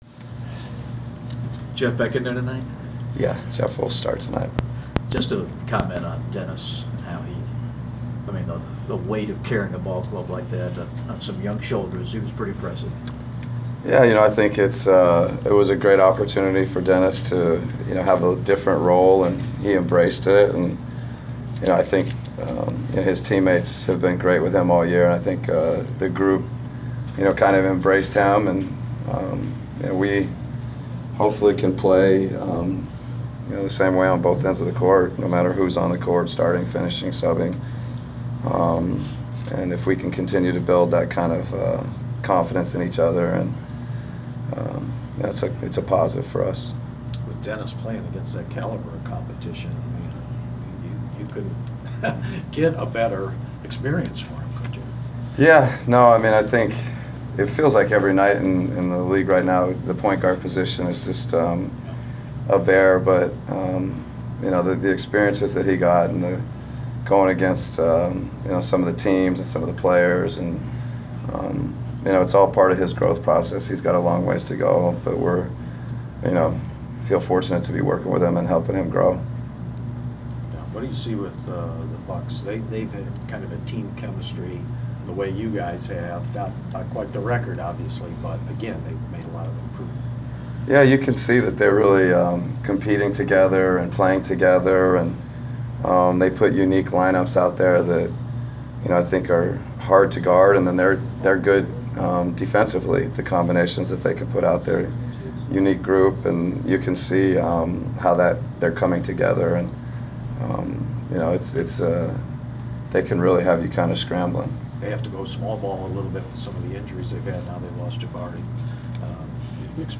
Inside the Inquirer: Pregame presser with Atlanta Hawks’ head coach Mike Budenholzer (12/26/14)
We attended the pregame presser of Atlanta Hawks’ head coach Mike Budenholzer before his team’s home contest against the Milwaukee Bucks on Dec. 26. Topics included the return of Jeff Teague, the play of Dennis Schroder and defending the Bucks’ offense.